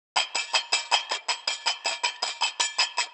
Teck-perc (afrikaaa).wav